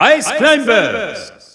Announcer pronouncing Ice Climbers in French PAL.
Ice_Climbers_French_EU_Announcer_SSBU.wav